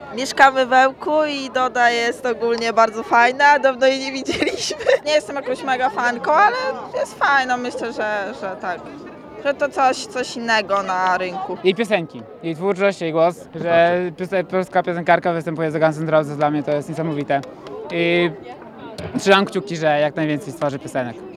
Tłumy fanów na sobotnim (06.08.) koncercie Dody.